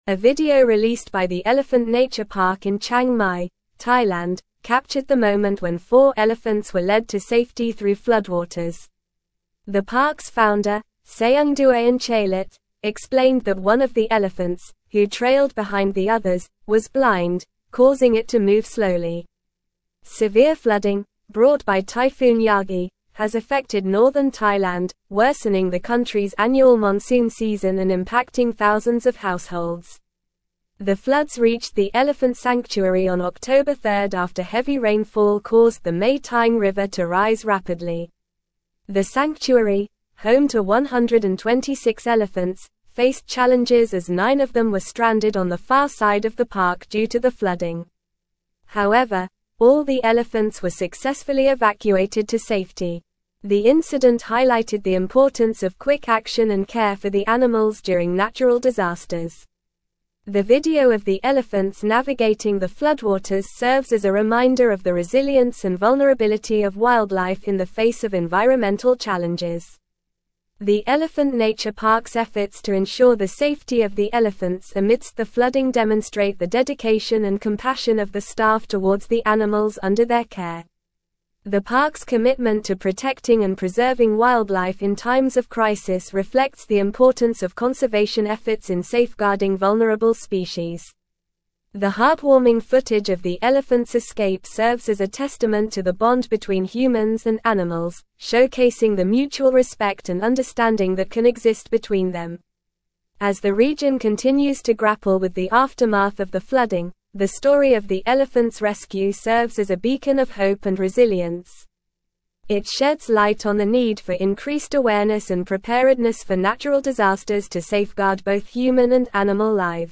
Normal
English-Newsroom-Advanced-NORMAL-Reading-Elephants-rescued-from-floodwaters-in-Thailand-sanctuary.mp3